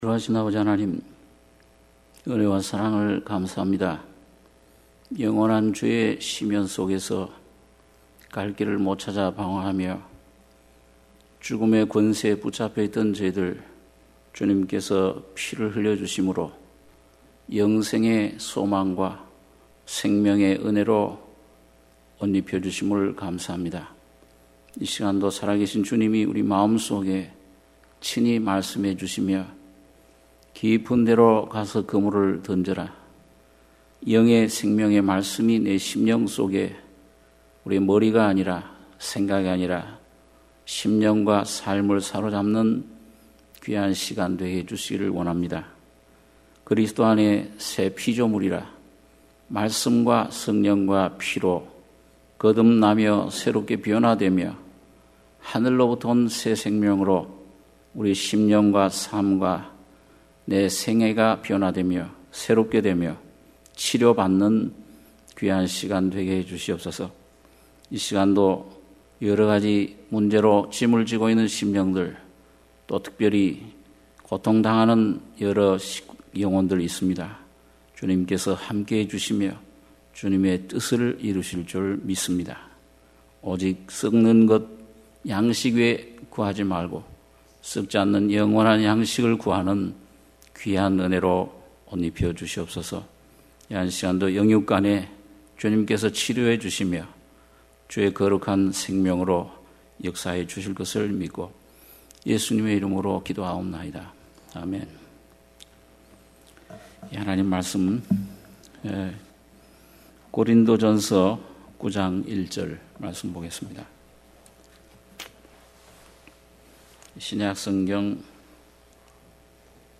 수요예배 - 고린도전서 9장 1~7절